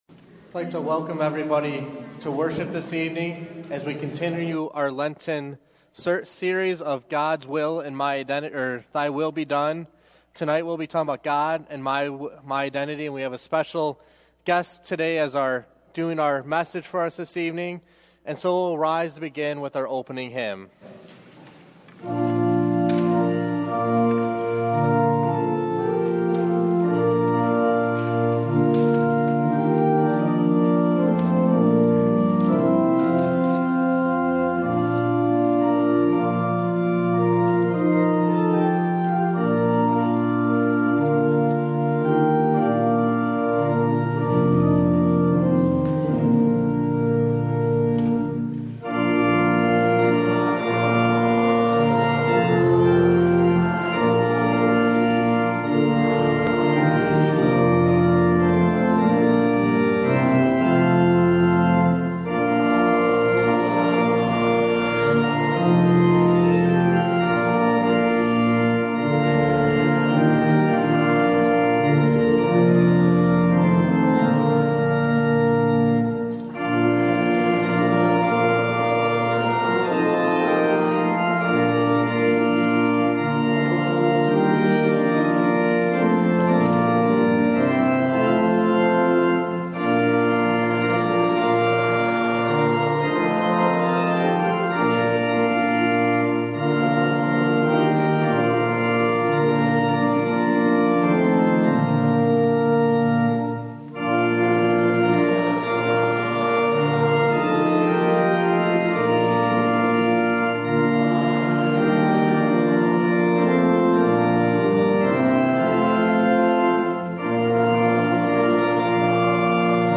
Mar 7 / Wed Eve – Lenten Worship Service audio